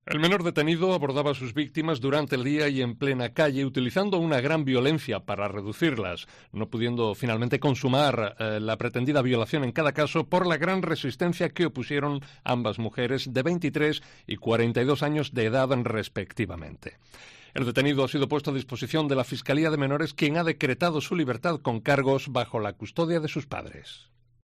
Detenido un menor en Puerto Serrano acusado de dos violaciones. Crónica